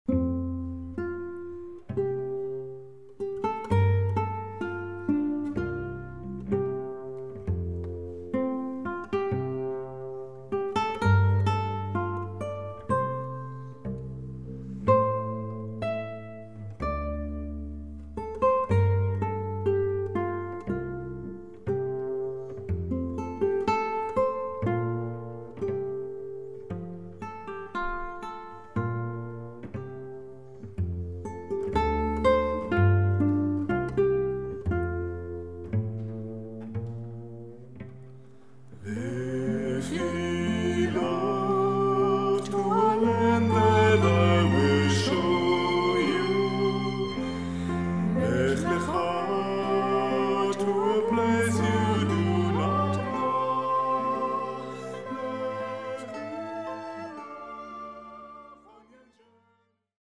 Shop / CDs / Vokal
jüdische Lieder